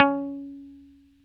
Index of /90_sSampleCDs/Roland L-CD701/GTR_Dan Electro/GTR_Dan-O 6 Str